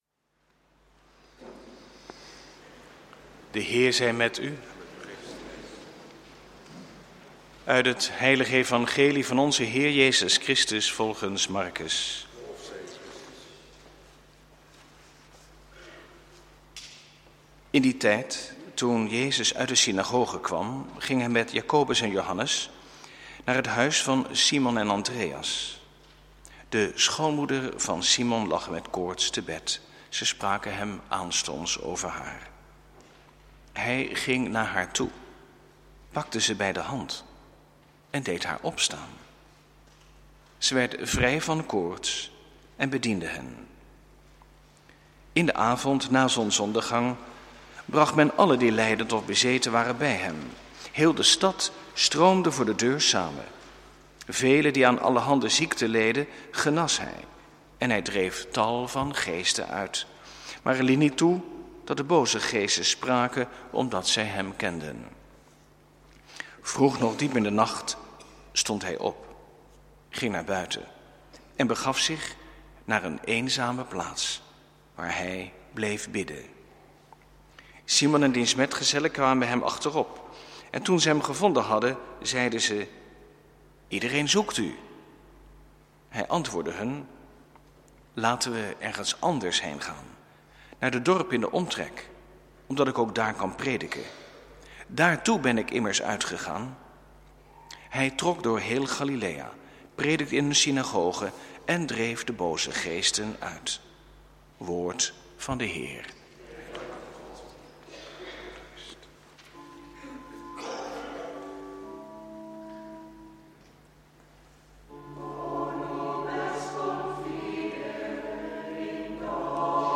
Lezingen
Eucharistieviering beluisteren (MP3)